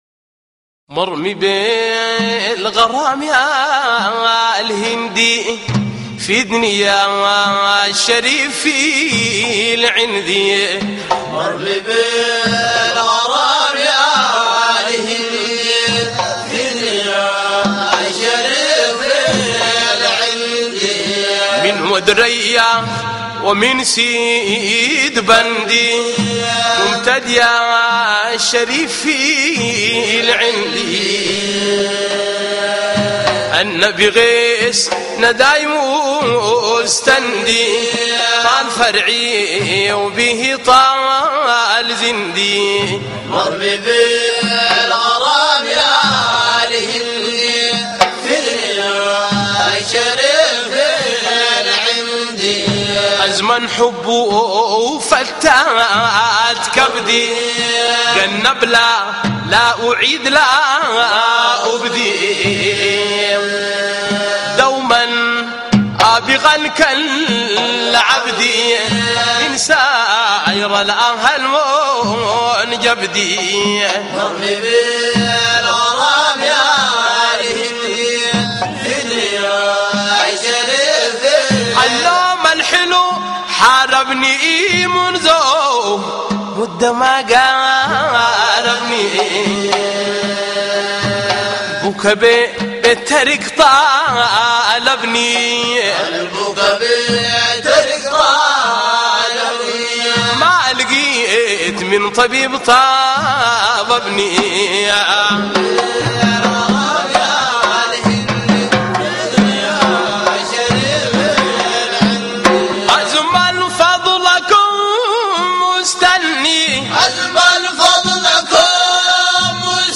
من روائع شيخ حياتي التي لا يمل العشاق والصالحون من الإستماع لها مهما ترددت أنشودته المباركة
صوت المادح الفنان